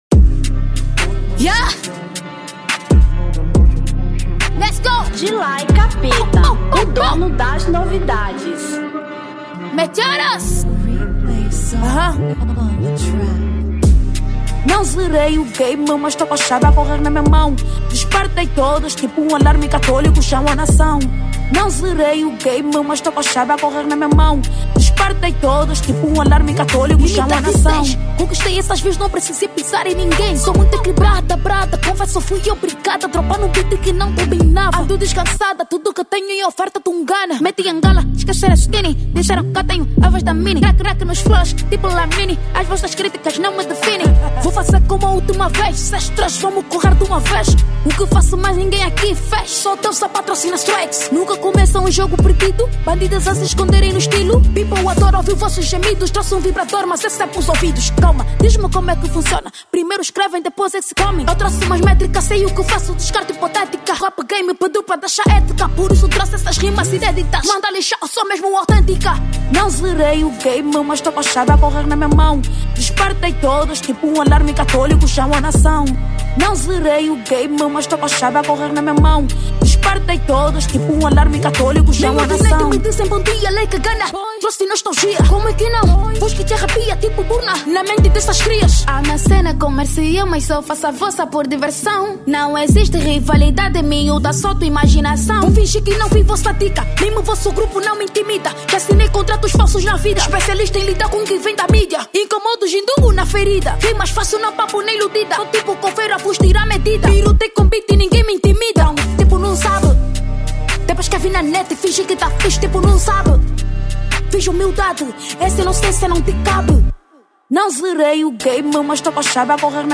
Rap 2025